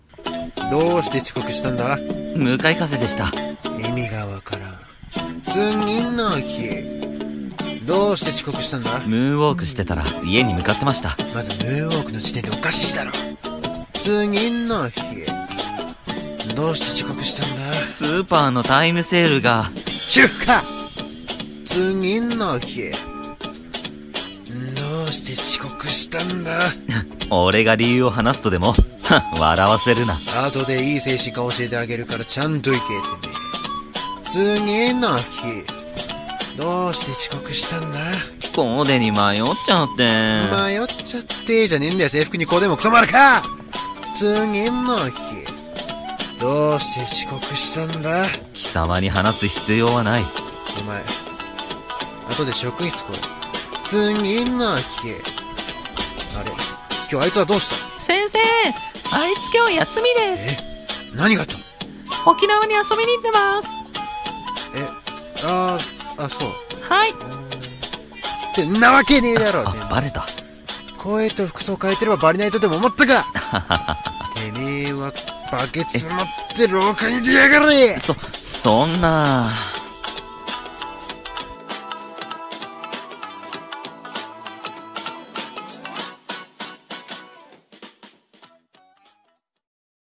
声劇「遅刻の理由」